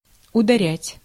Ääntäminen
IPA: /slaːn/